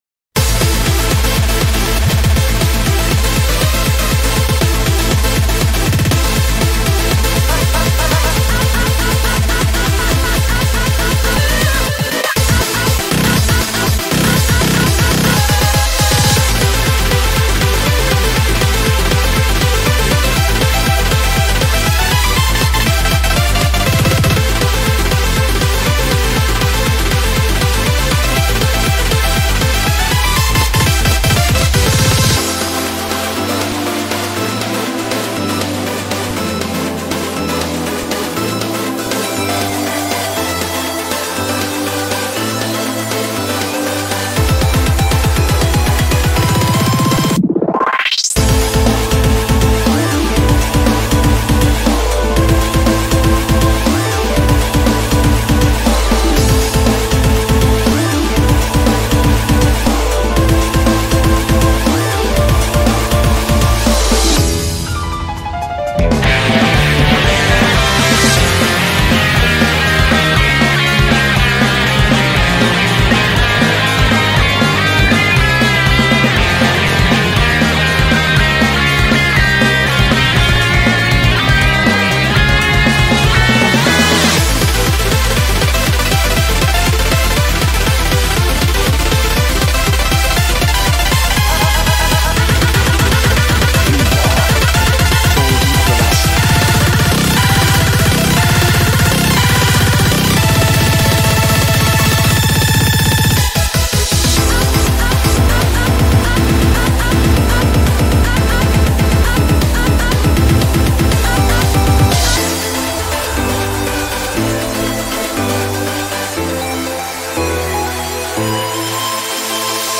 BPM120-480
Audio QualityPerfect (Low Quality)